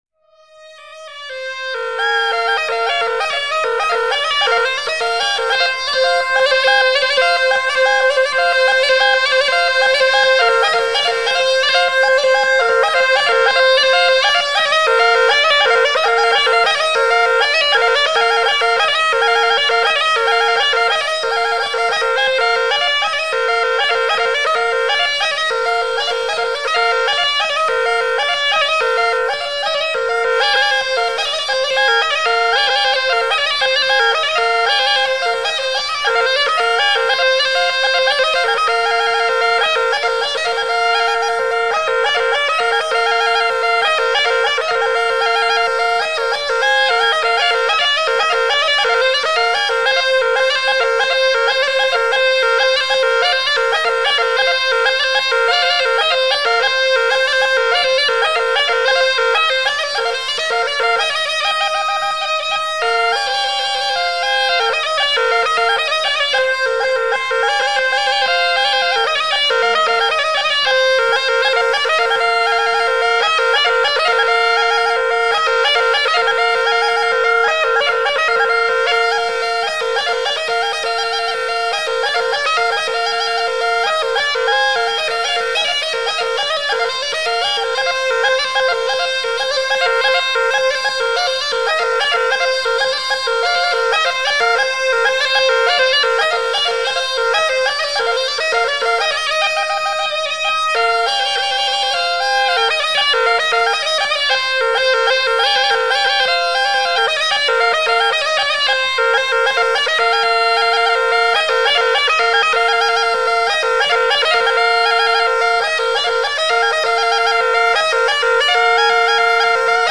ΤΡΑΓΟΥΔΙΑ ΚΑΙ ΣΚΟΠΟΙ ΑΠΟ ΤΑ ΔΩΔΕΚΑΝΗΣΑ
14.Ίσσος τσαμπουνιστός